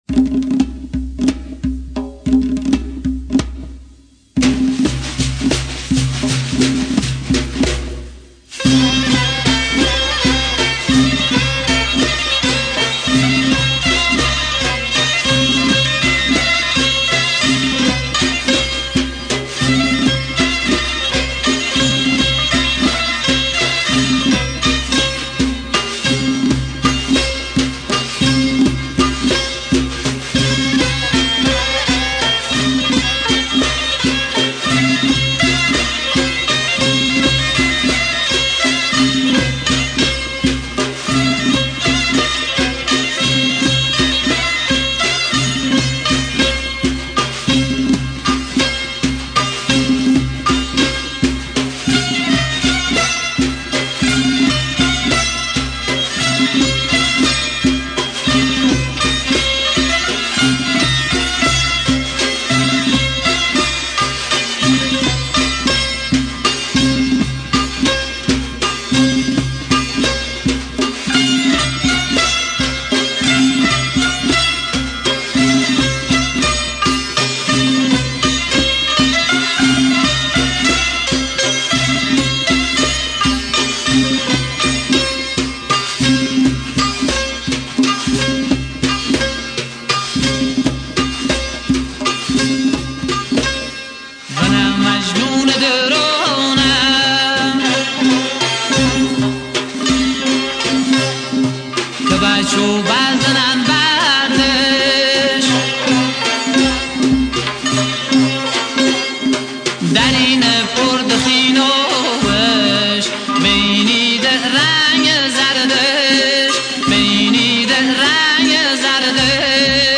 Lori music